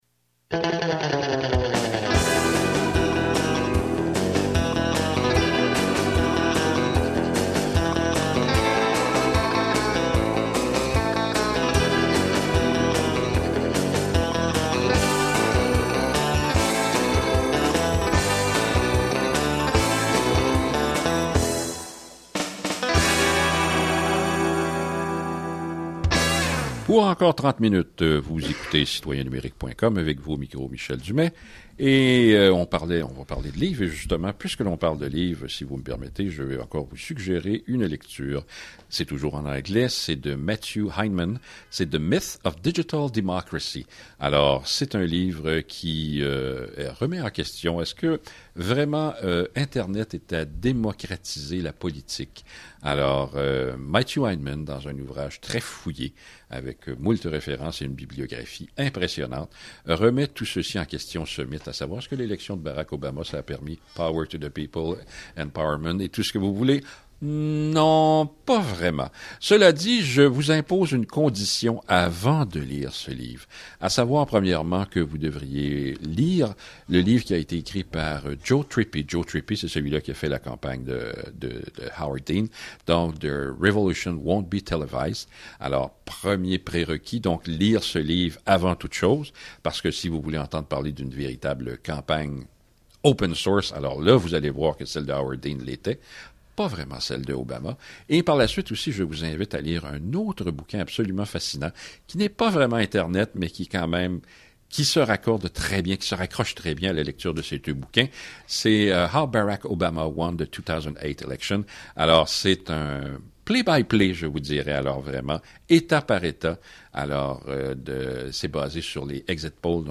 Il y a aussi eu conversation à l’antenne de CIBL